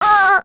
Rehartna-Vox_Damage_03.wav